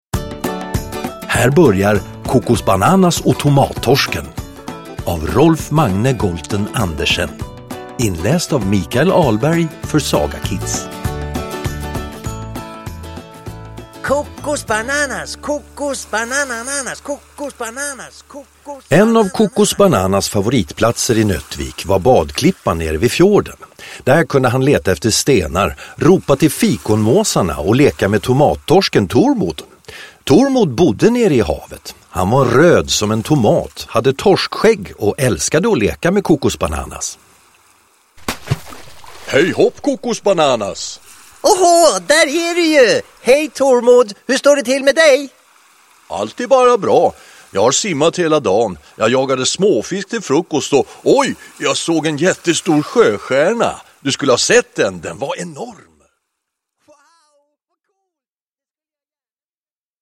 Med härliga ljudeffekter och musik bjuder Kokosbananas på underhållning för hela familjen!
• Ljudbok